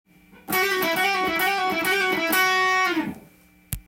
②のフレーズは、速弾き時に使用するEマイナースケールの繰り返しフレーズです。